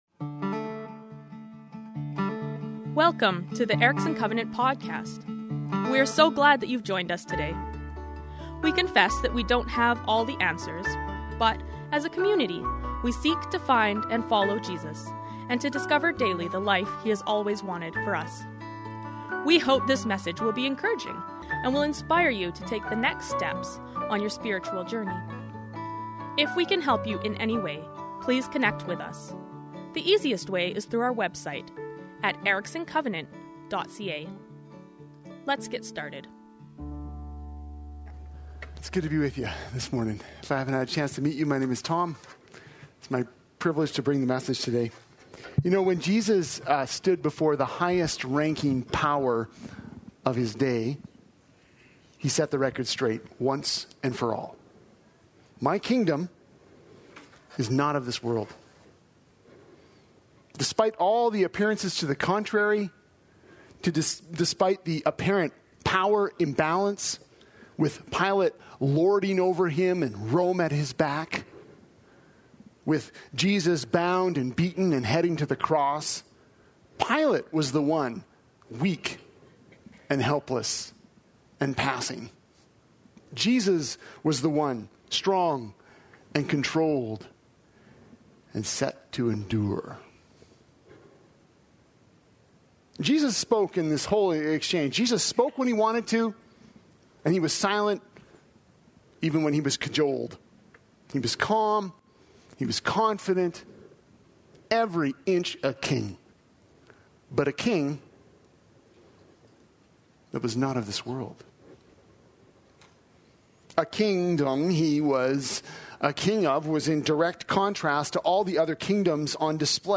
Kingdom Priorities: A Special Election Message